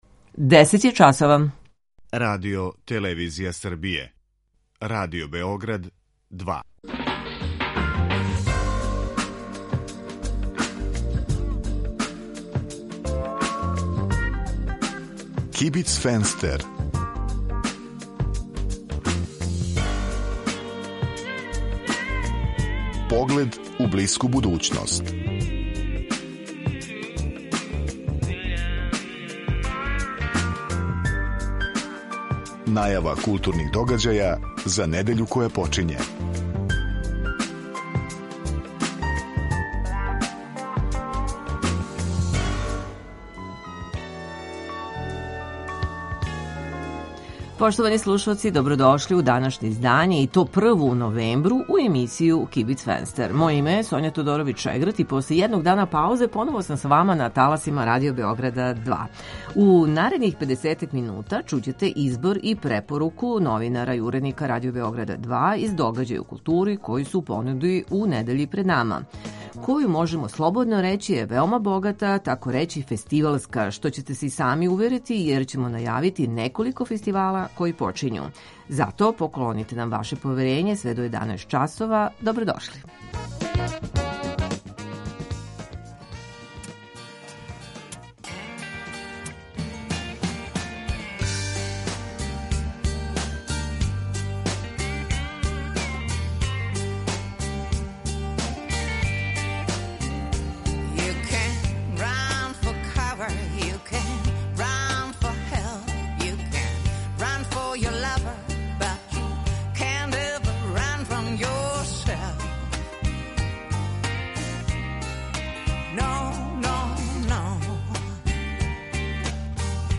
Најавићемо почетак Бемуса и 17. филмски фестивал Слободна зона. Осим тога, чућете избор и препоруку новинара и уредника Радио Београда 2 из догађаја у култури који нас очекују у недељи пред нама.